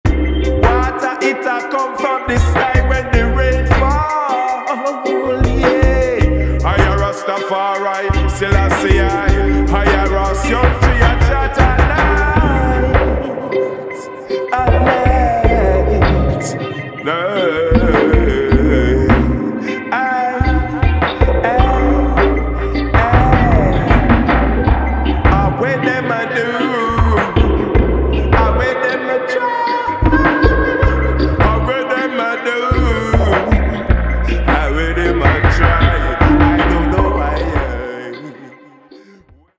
伝統的な要素と先鋭的な要素がブレンドされたレゲエ作品に仕上がっています。